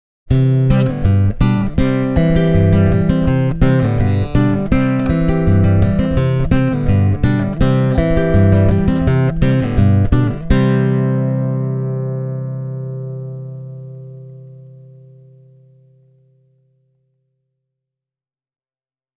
Both soundbites start with the neck singlecoil, then switch to both pickups with the humbucker split, and lastly to the split humbucker on its own. The last two motifs are the combined setting, followed by the bridge pickup on its own, both with the full humbucker switched on:
Yamaha Pacifica 611H – crunch